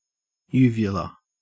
Pronunciation/ˈjuːvjʊlə/
En-au-uvula.ogg.mp3